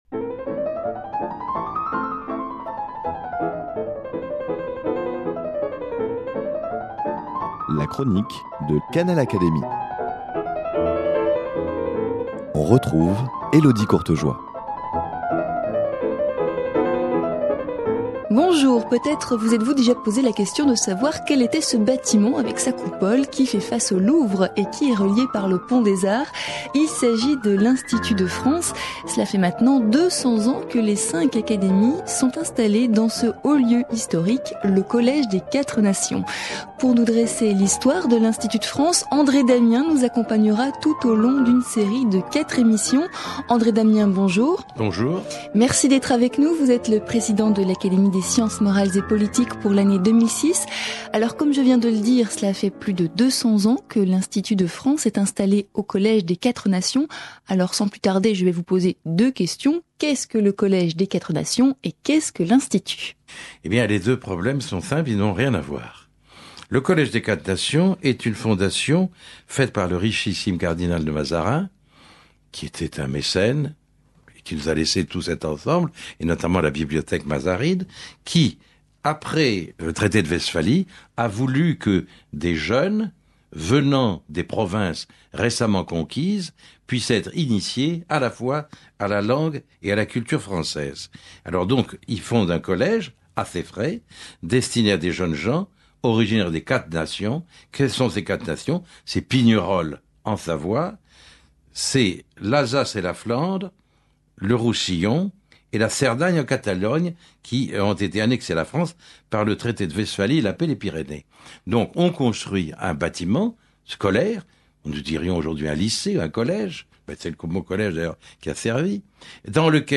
invité de Canal Académie.